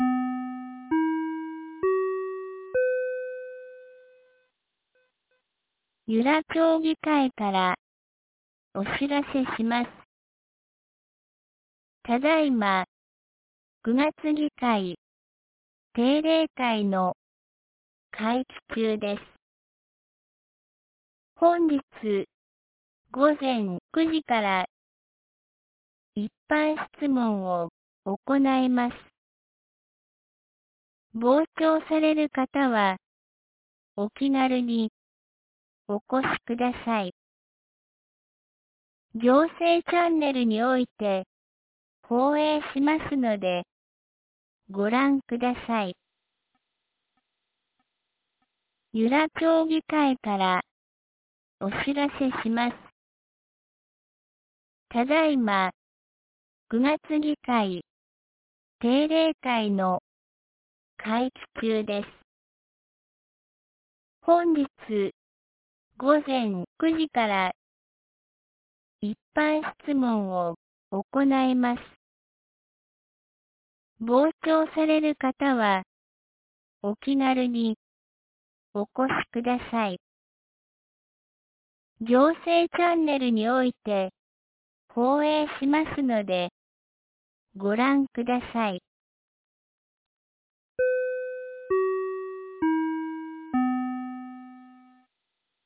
2025年09月11日 07時52分に、由良町から全地区へ放送がありました。